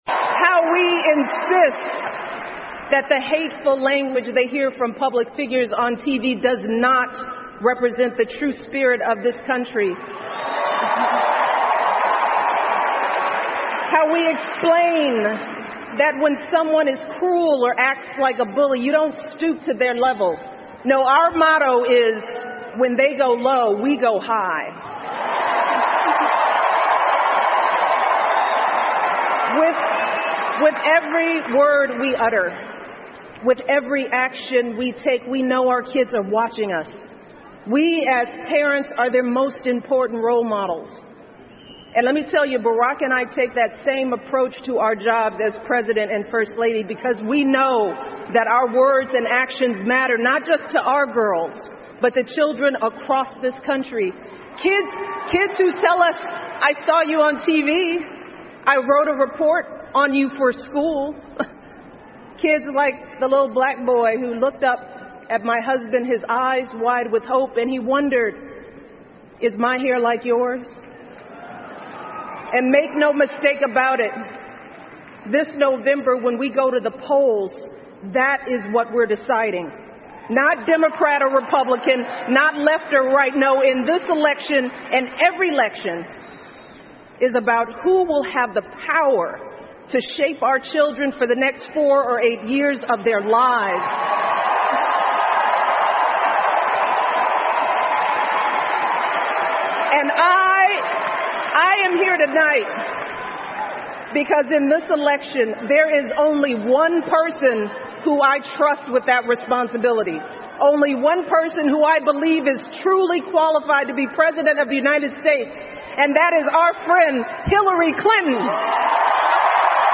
美国总统大选演讲 听力文件下载—在线英语听力室